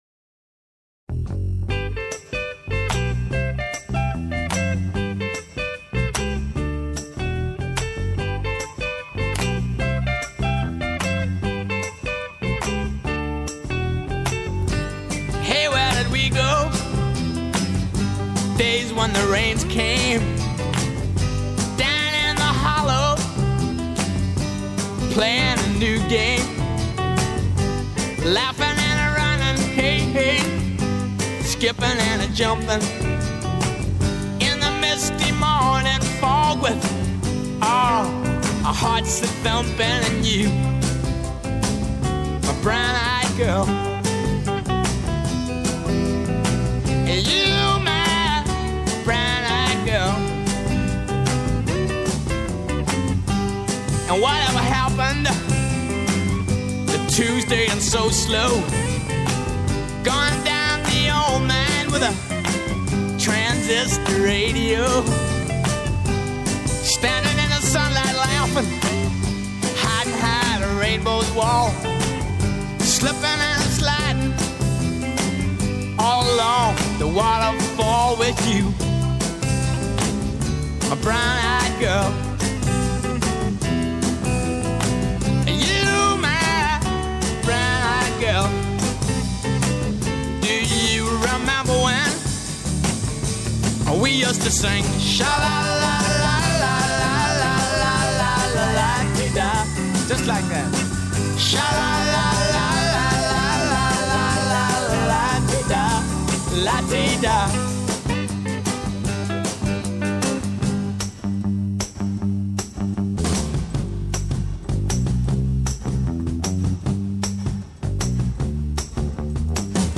A COLORADO SPRINGS BASED CLASSIC ROCK BAND!